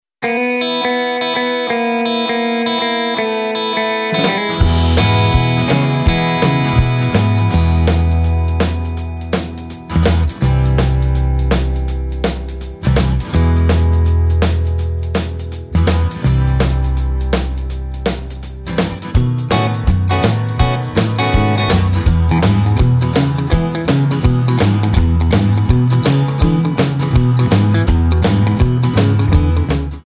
Tonart:E ohne Chor